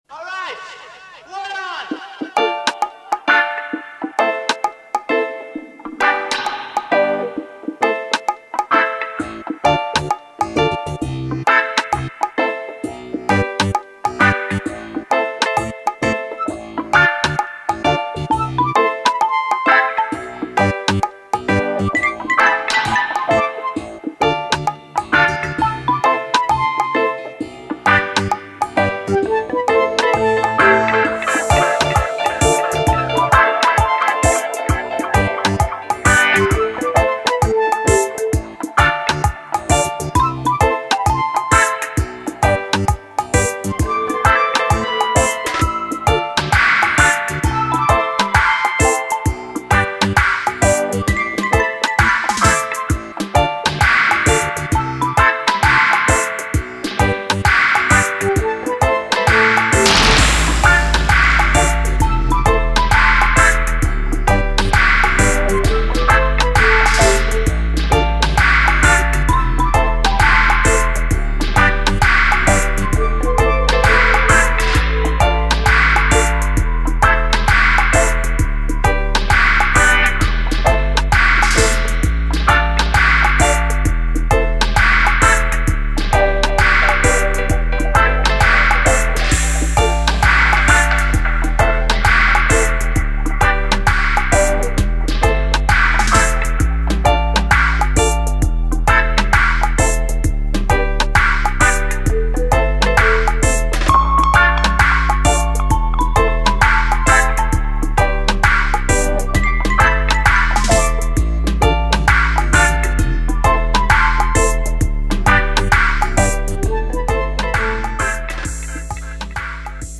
congo-bongo-dub-sample-ynvK0